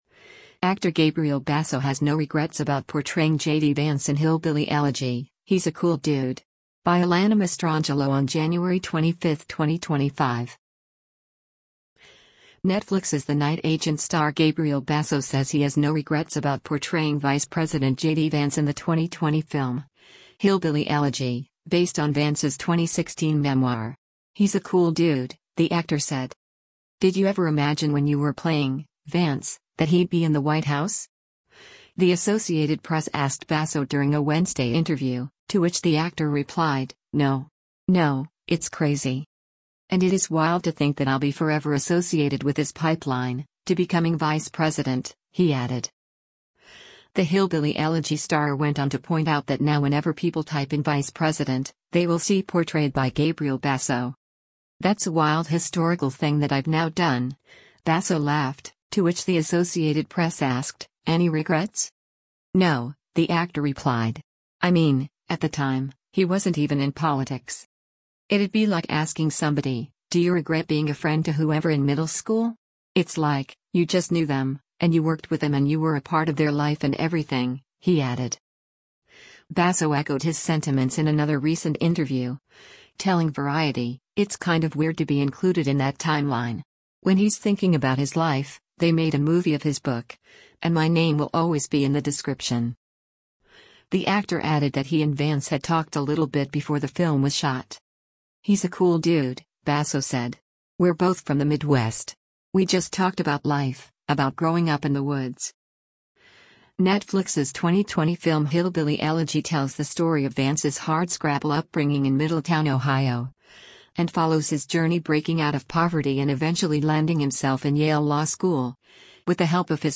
“Did you ever imagine when you were playing [Vance] that he’d be in the White House?” the Associated Press asked Basso during a Wednesday interview, to which the actor replied, “No. No, it’s crazy.”
“That’s a wild historical thing that I’ve now done,” Basso laughed, to which the Associated Press asked, “Any regrets?”